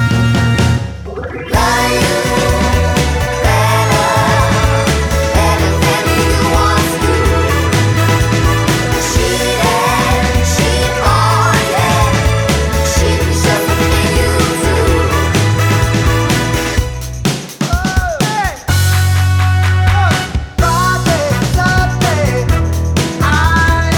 no Backing Vocals Dance 3:33 Buy £1.50